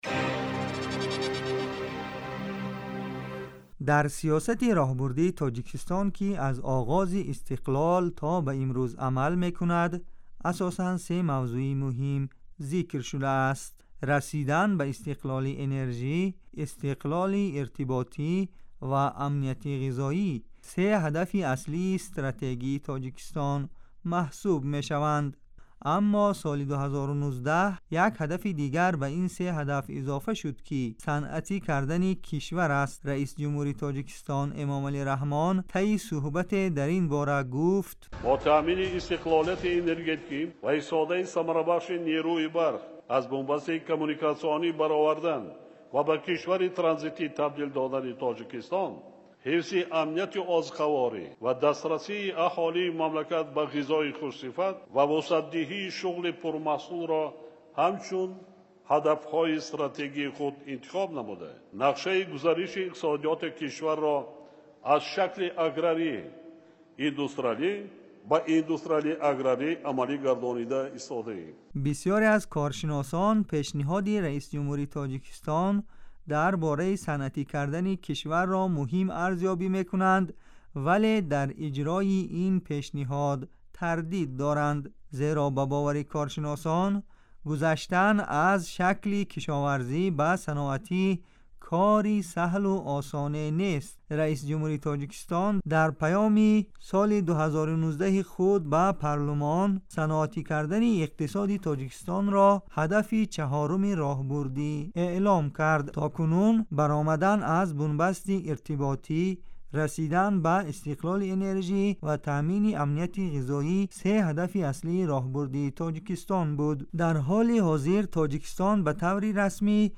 гузорише вижа